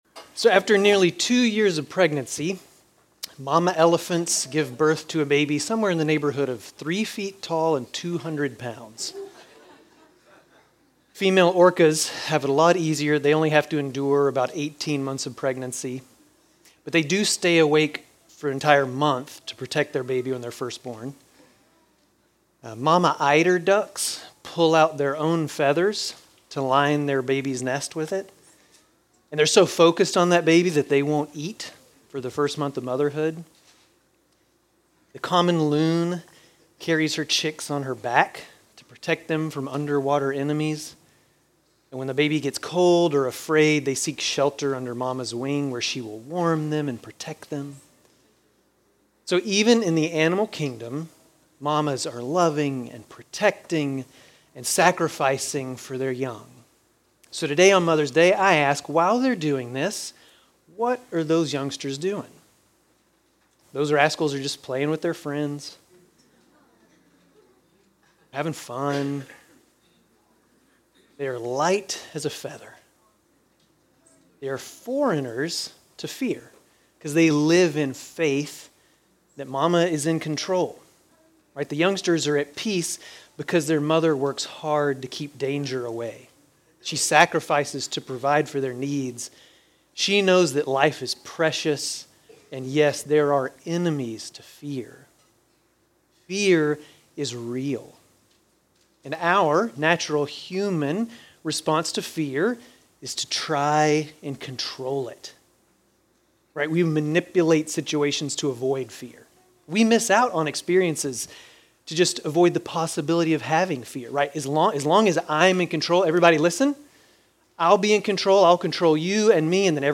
Grace Community Church Dover Campus Sermons 5_11 Dover Campus May 11 2025 | 00:28:15 1x 00:00 / 00:28:15 Subscribe Share RSS Feed Share Link Embed